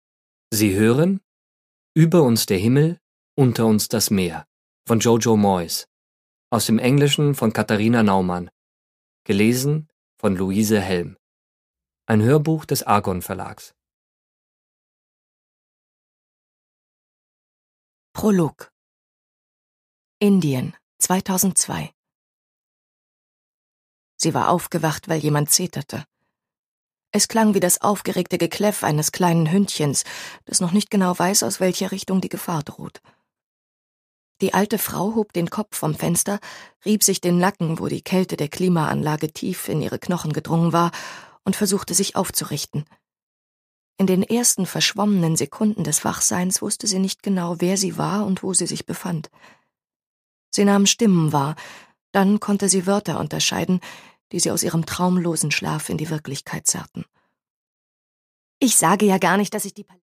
Jojo Moyes: Über uns der Himmel, unter uns das Meer (Gekürzte Lesung)
Produkttyp: Hörbuch-Download
Gelesen von: Luise Helm